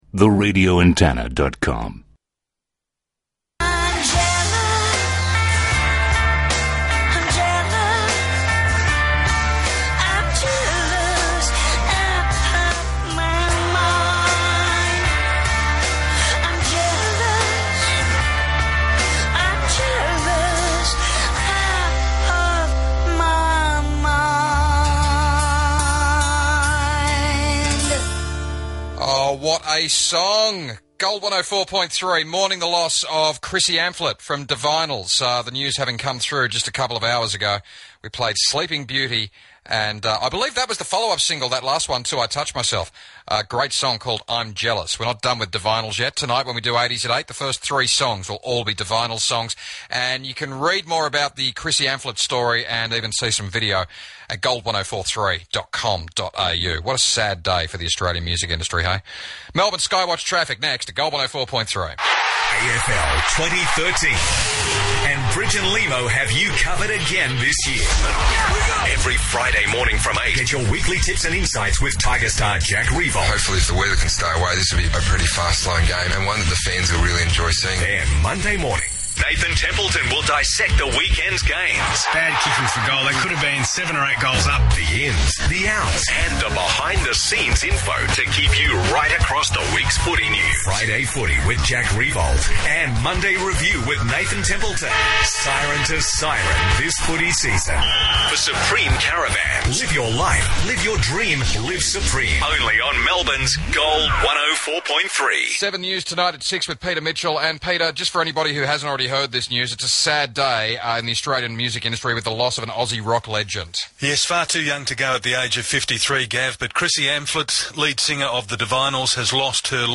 Hat’s off to this Jock for caring enough to immediately put a tribute on air, sadly it would seem spontaneity is lacking these days, my locals must have been ASLEEP!